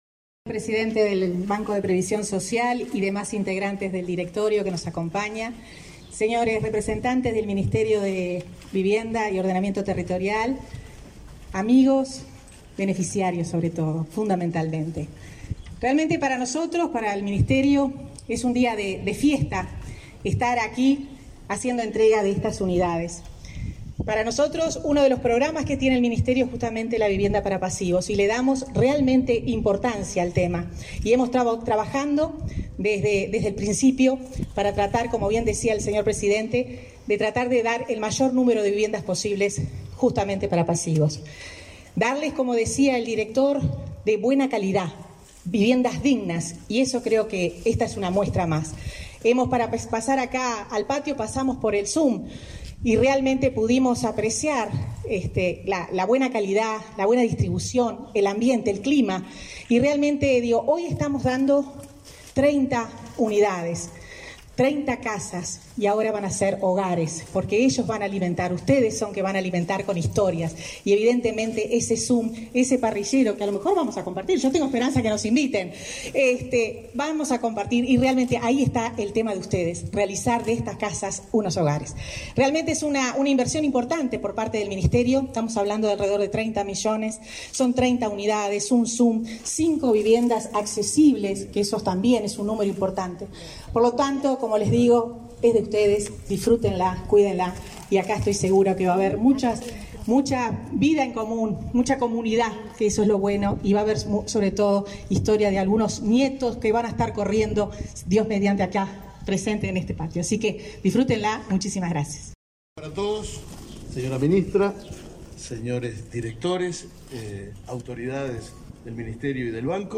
Conferencia de prensa por la entrega de viviendas a jubilados y pensionistas de Montevideo
Participaron del evento la ministra Irene Moreira y el presidente del BPS, Alfredo Cabrera.